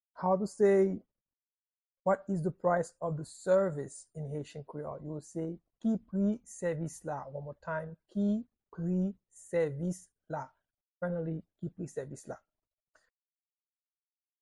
Pronunciation and Transcript:
How-to-say-Whats-the-price-of-the-service-in-Haitian-Creole-–-Ki-pri-sevis-la-pronunciation.mp3